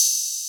openhat [gang gang](1).wav